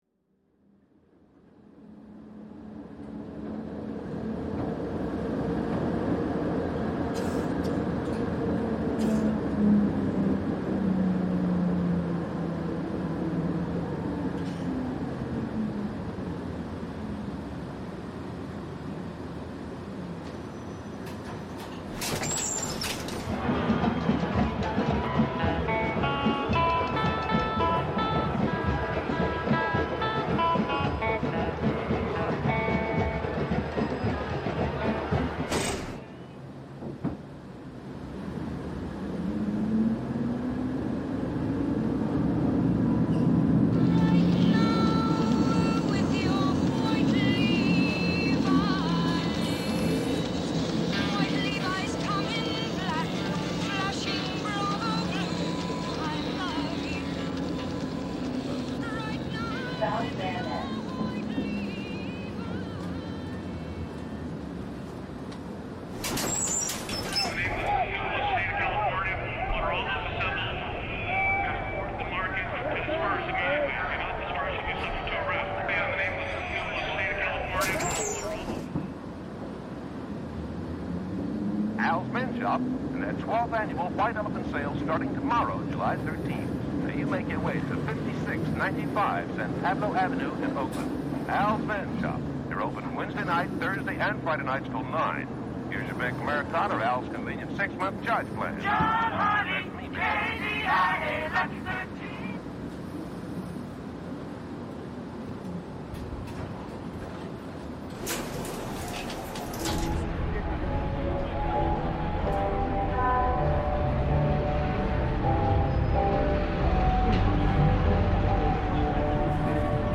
San Francisco streetcar reimagined